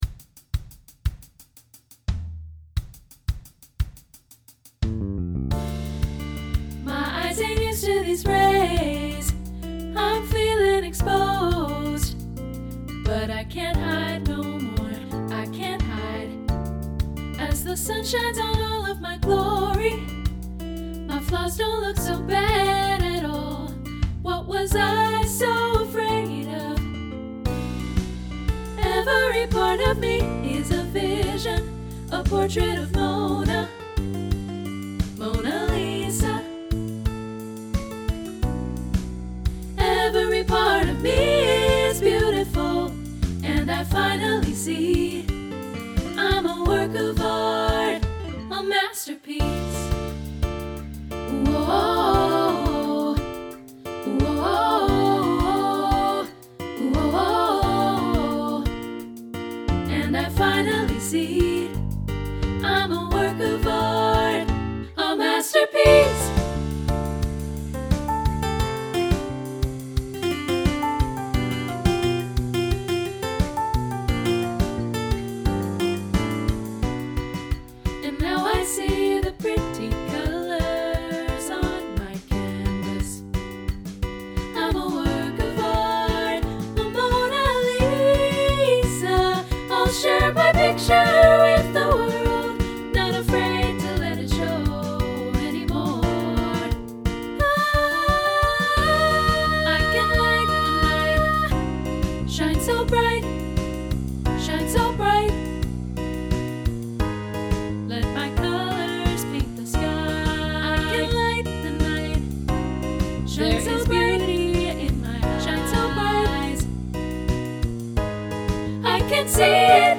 contemporary choral SSA arrangement
Check out the studio demo (MIDI instruments + live voices):
Instrumentation: Piano, Guitar, Bass, Drumset
SSAA Pop Choral